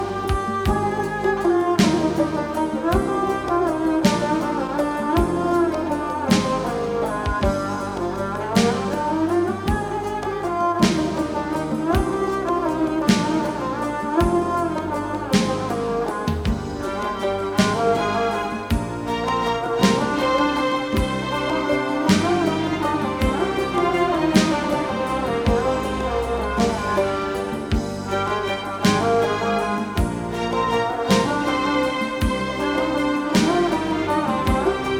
Halk